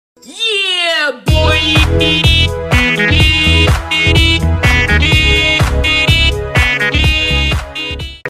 yeah boiii i i i sound effects free download
yeah boiii i i i - Meme Effect Sound